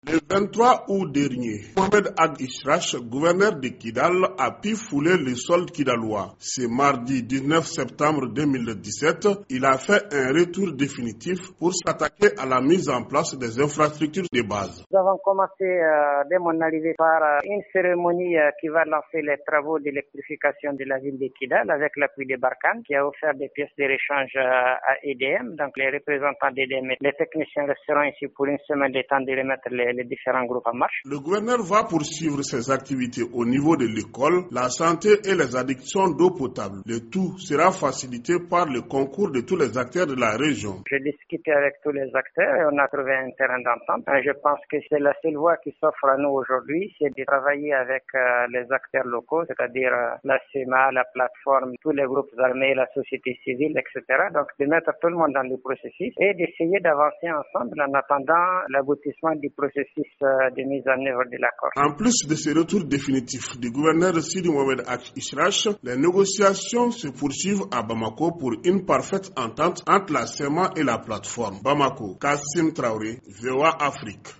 Compte-rendu